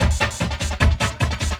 45 LOOP 11-L.wav